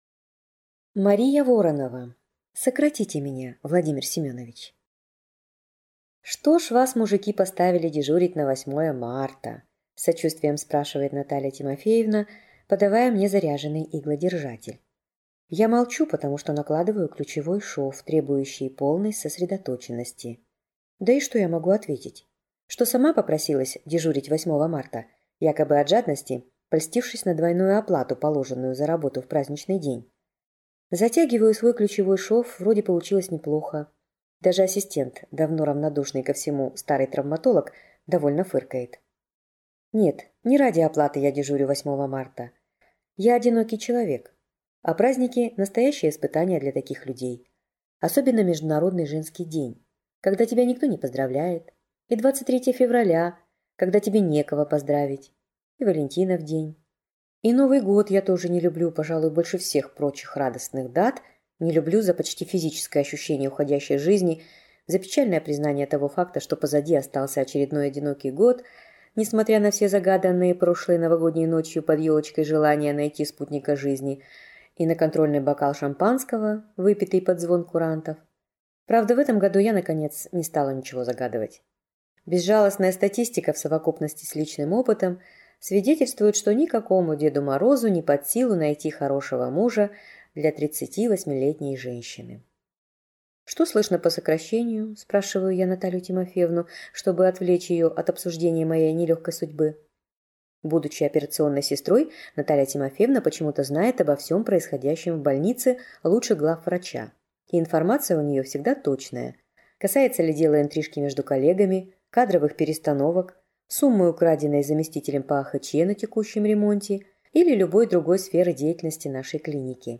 Аудиокнига Сократите меня, Владимир Семенович!